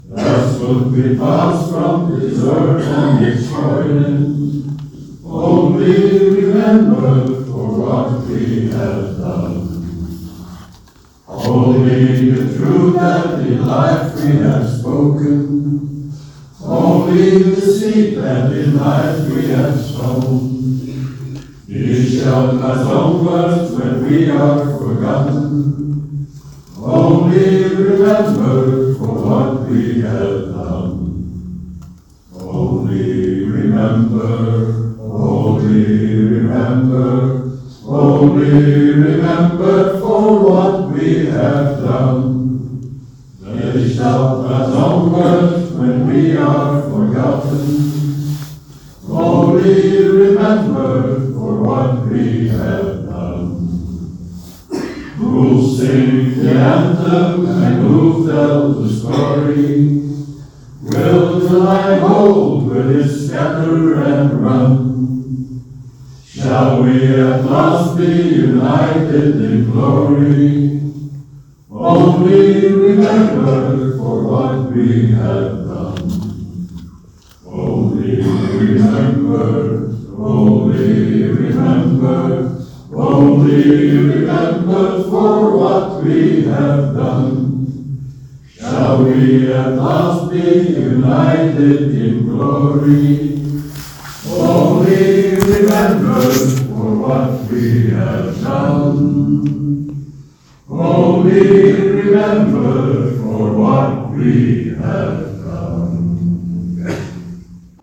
tijdens de herdenking te Breendonk
Live opname van "Only Remembered"
Breendonk 7 dec. 2024